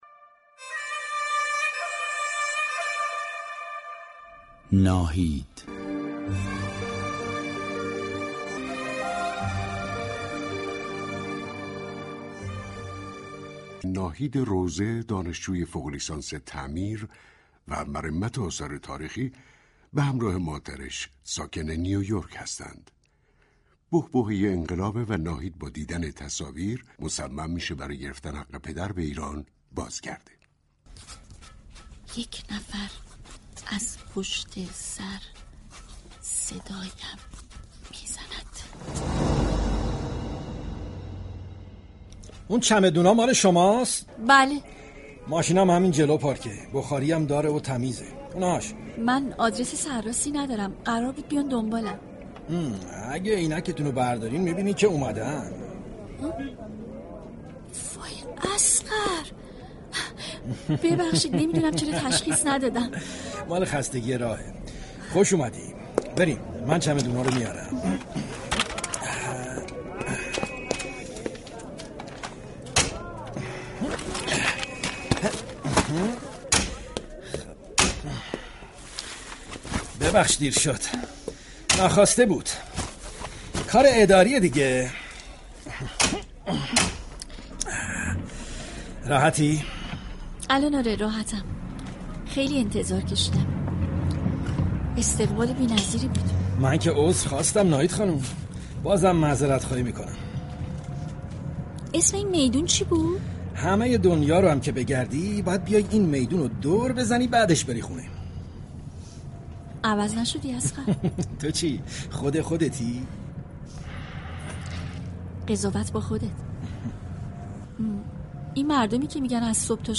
پخش سریال اجتماعی جدید